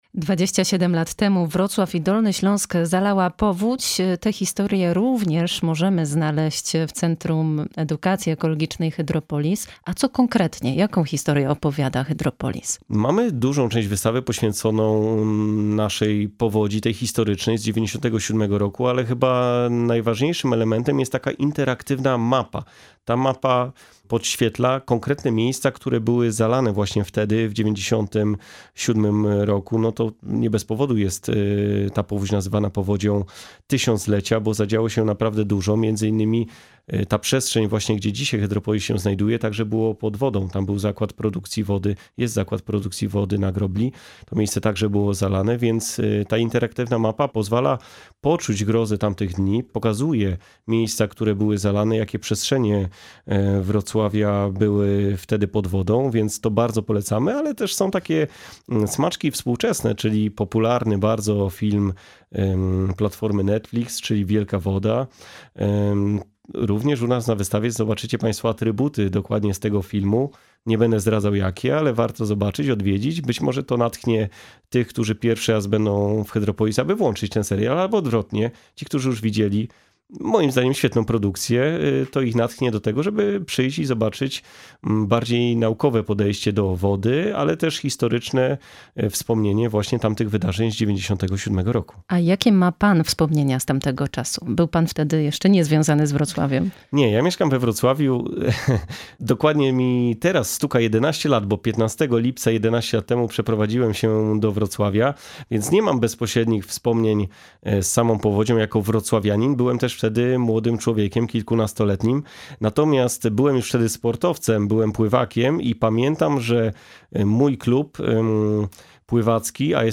Pytamy w wywiadzie.